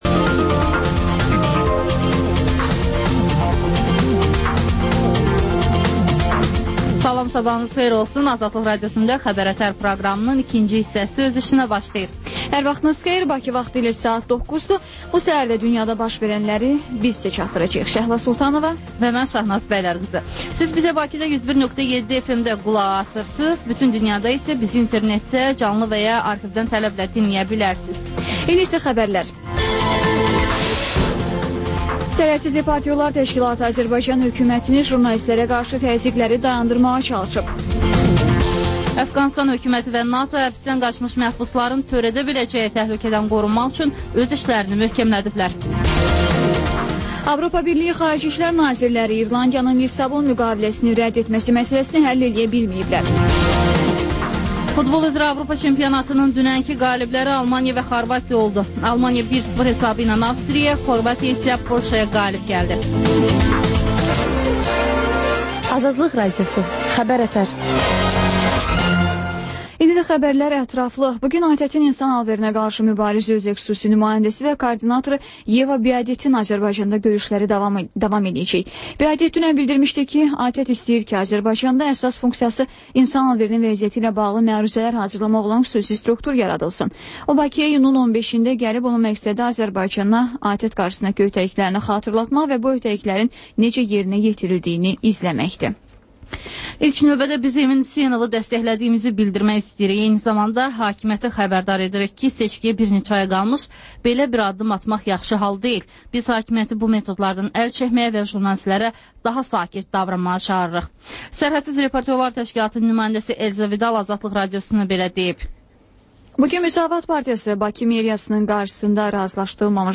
Xəbər-ətər: xəbərlər, müsahibələr, sonra İZ mədəniyyət proqramı